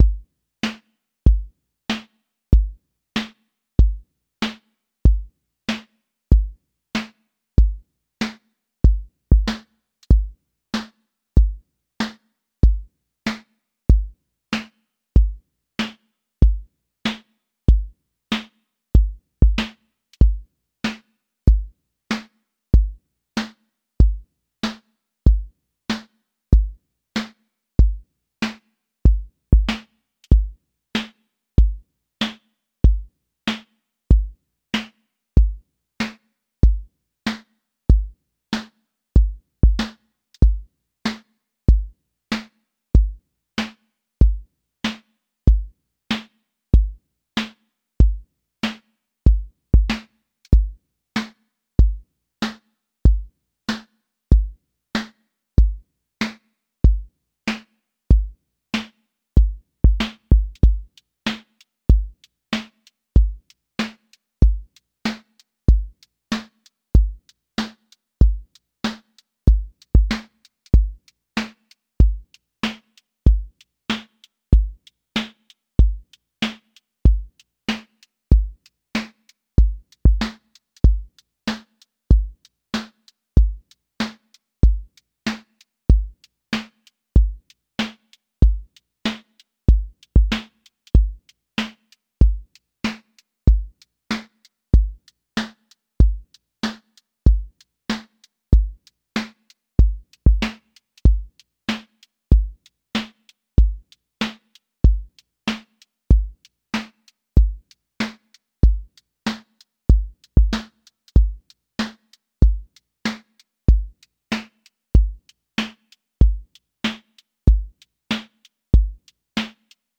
QA Listening Test boom-bap Template: boom_bap_drums_a
A long-form boom bap song with recurring sections, edits within the pattern every 4 bars, clear returns, evolving pocket, a sparse intro, a fuller middle, and a stripped return over two minutes.
• voice_kick_808
• voice_snare_boom_bap
• voice_hat_rimshot
• tone_warm_body
• fx_space_haze_light